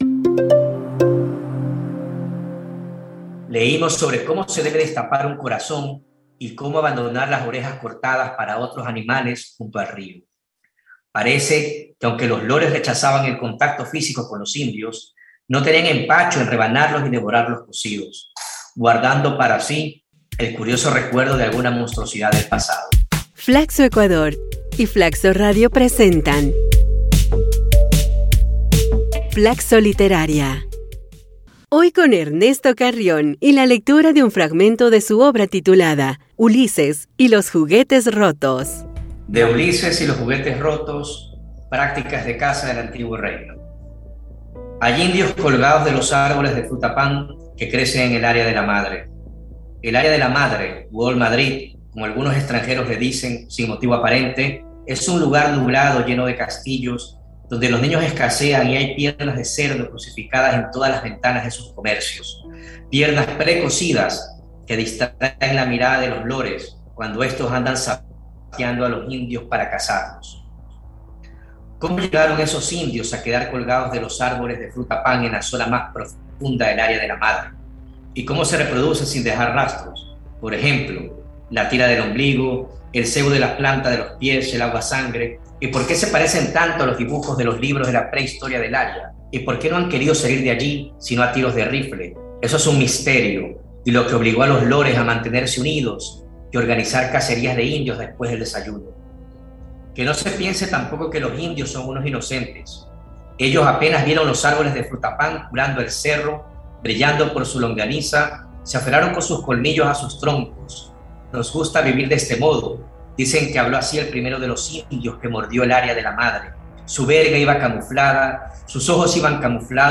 FLACSO Literaria es una iniciativa de lectura de cuentos y fragmentos de novelas que busca promover la literatura ecuatoriana, que reúne a 21 destacados escritores y escritoras quienes leerán sus obras.